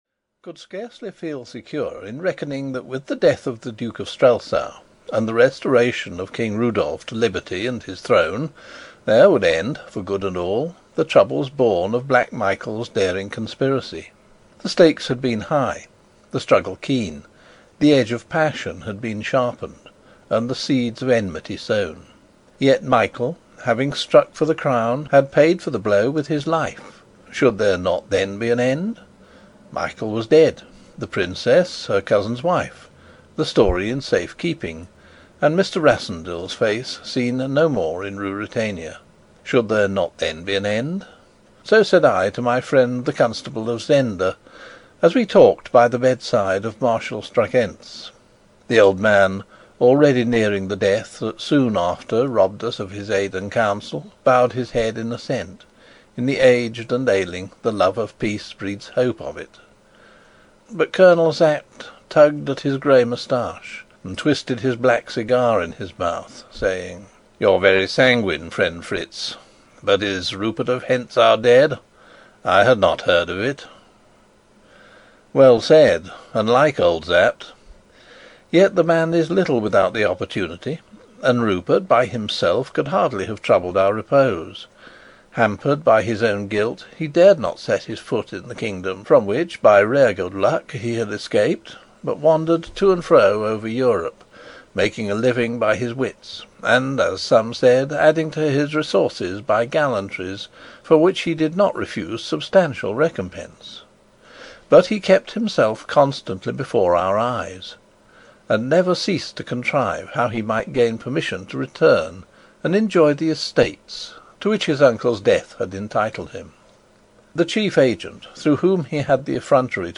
Rupert of Hentzau (EN) audiokniha
Ukázka z knihy